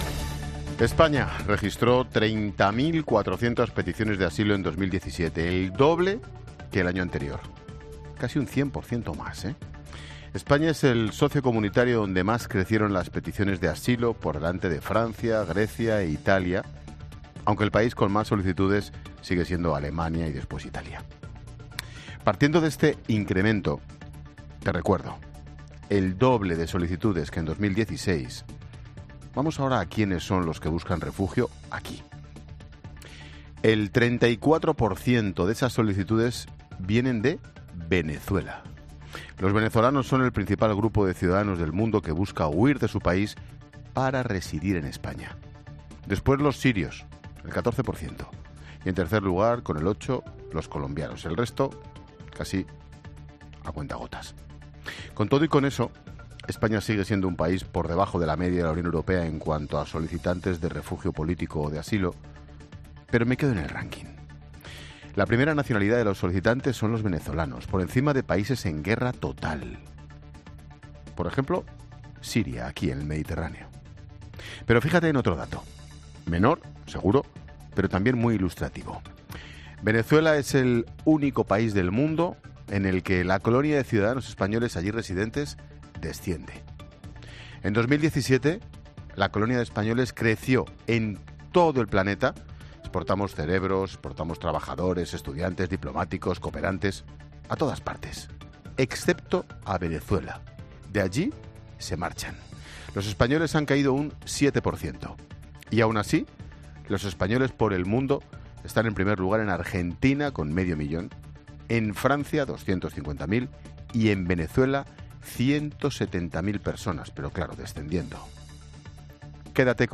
Monólogo de Expósito
Comentario de Ángel Expósito sobre las solicitudes de asilo en nuestro país.